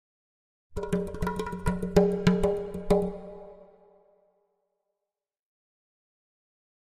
Drums Exotic Percussion Beat Finale 2